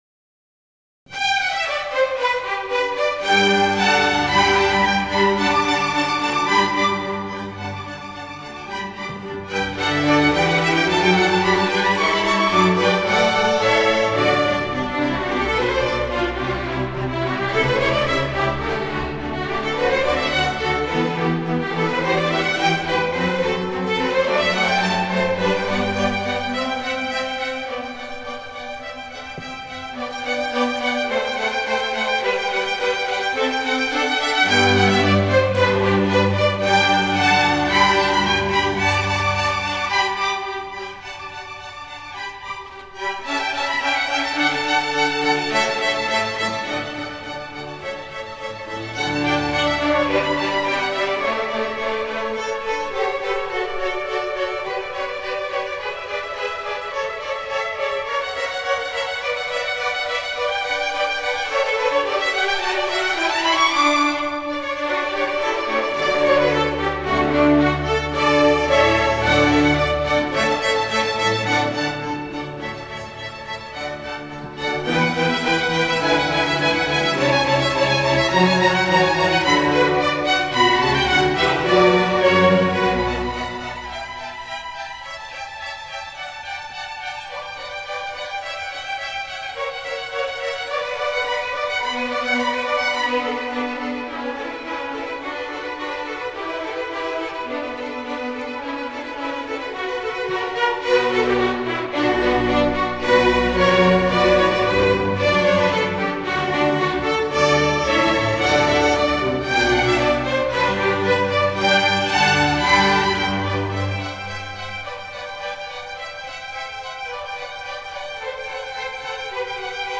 CONDUCTING AUDIO SAMPLES
Winter Park High School Philharmonic Orchestra, Winter Park, Florida